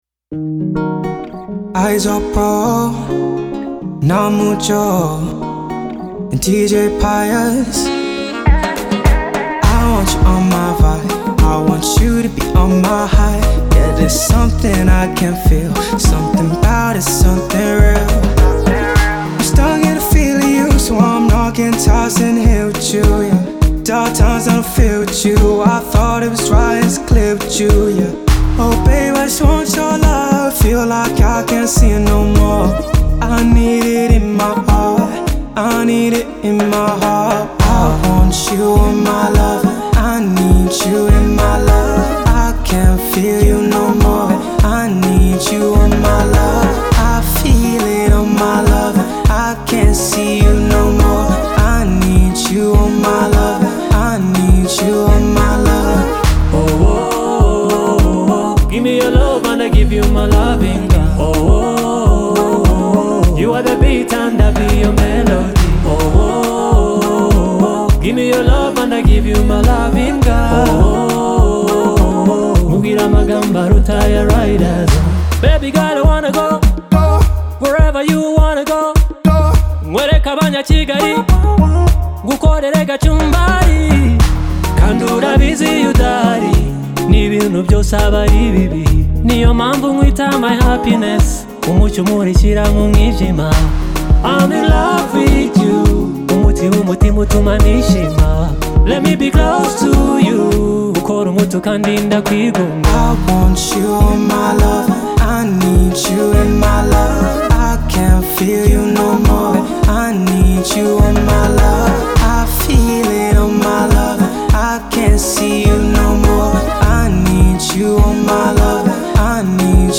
R&B Pop